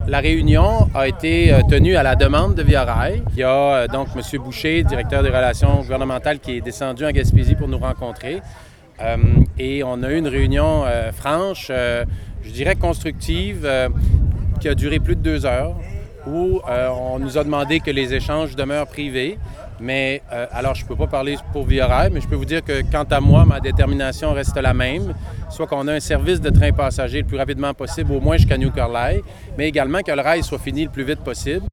Pour le député fédéral de la région, Alexis Deschênes, la priorité reste la même, soit le retour d’une voie ferroviaire praticable jusqu’à Gaspé le plus rapidement possible :